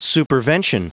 Prononciation du mot supervention en anglais (fichier audio)
Prononciation du mot : supervention